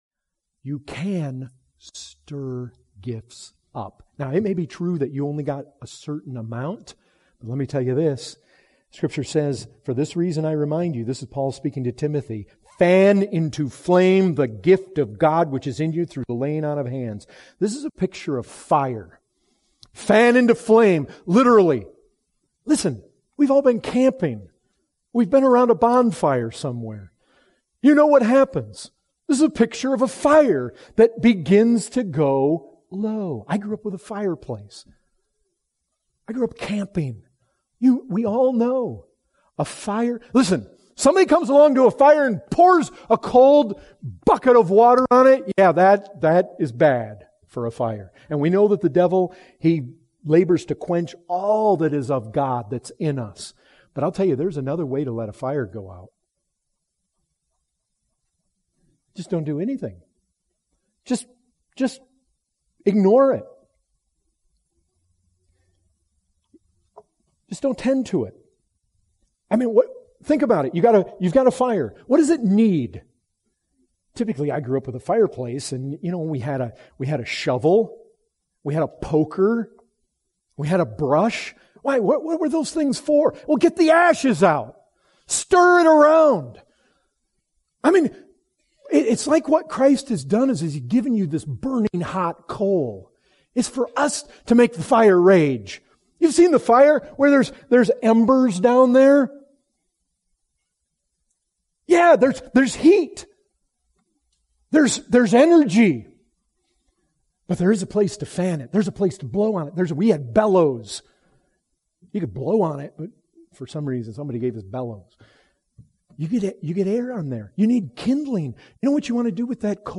Excerpt from the full sermon, “Christ’s Gifts to Men“.